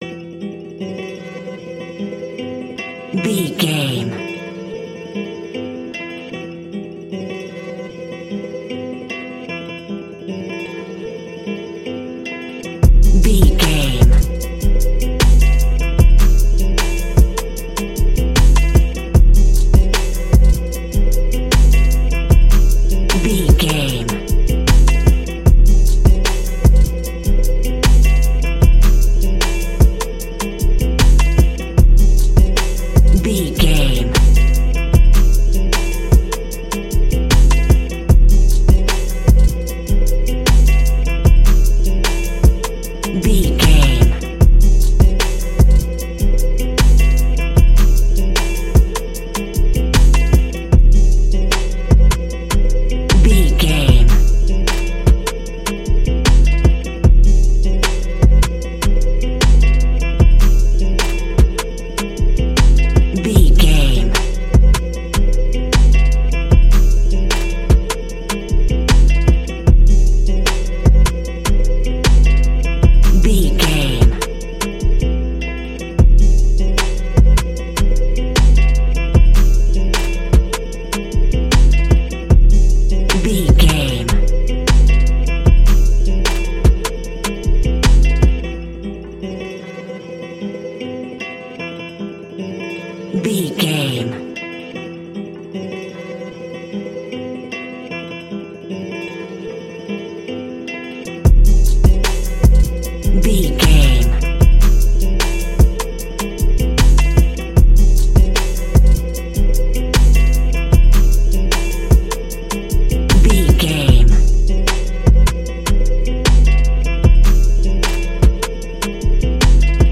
Aeolian/Minor
aggressive
intense
driving
bouncy
energetic
dark
drum machine
acoustic guitar
synthesiser